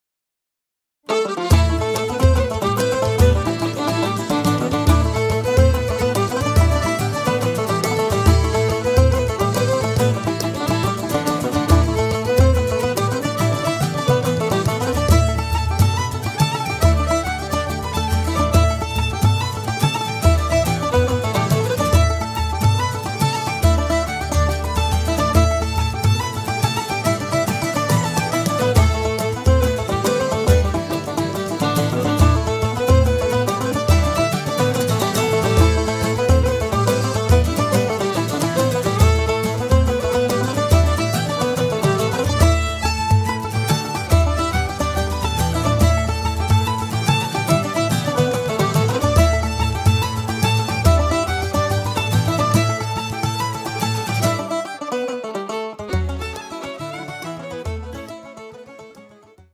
Fiddle/vocals/Glockenspiel
Bodrhan/Percussion
Guitars
Banjo/Mandolin
Piano/Piano Accordion